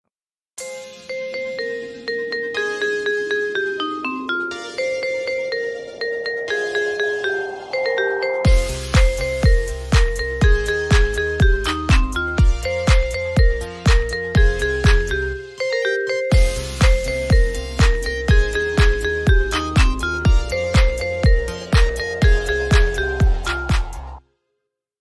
Mariba Remix